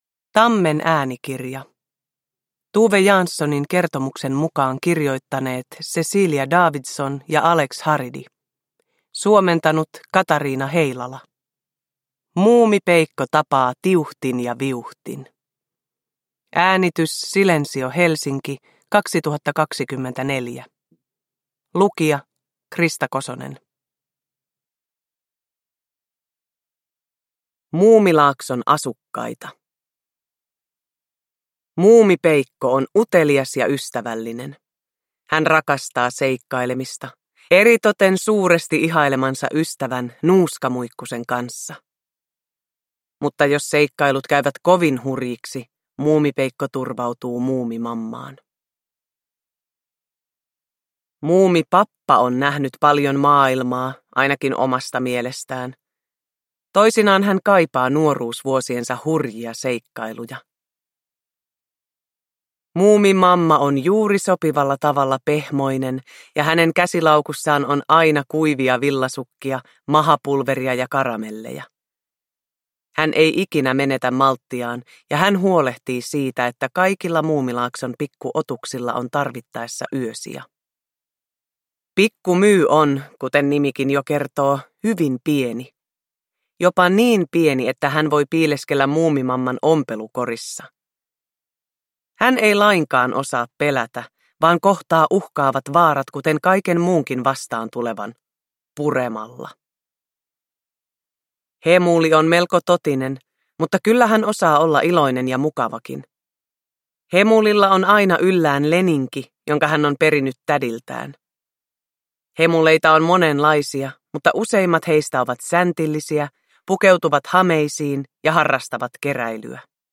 Muumipeikko tapaa Tiuhtin ja Viuhtin – Ljudbok
Uppläsare: Krista Kosonen